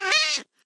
mob / cat / hit2.ogg